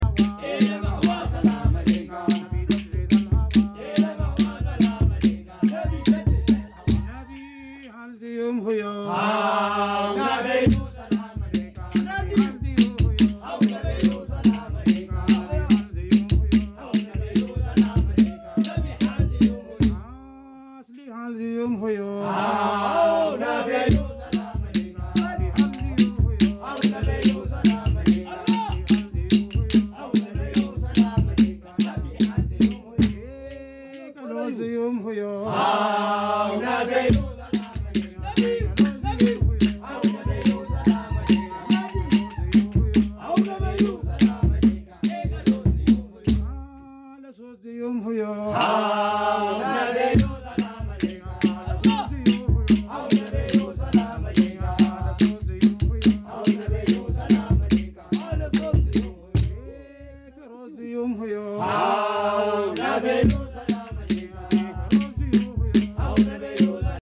Zikri Live